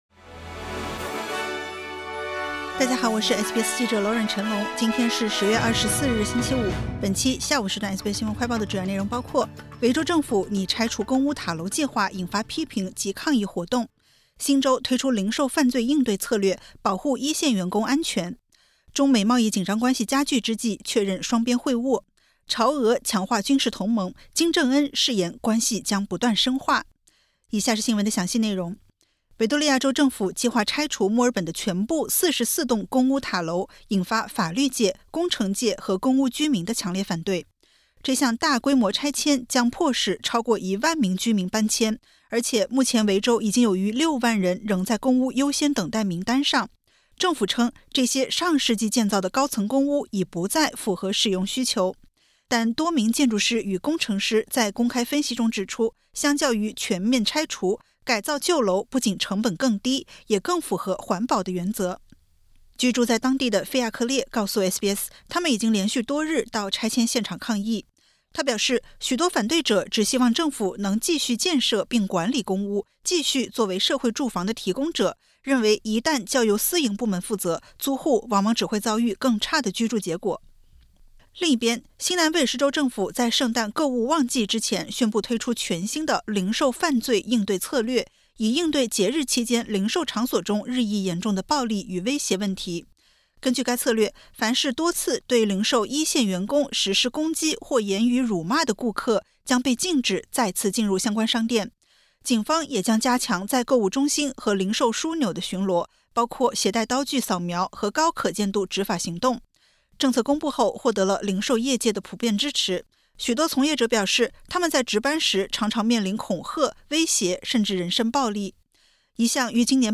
【SBS新闻快报】维州政府拟拆除公屋塔楼计划引发批评及抗议活动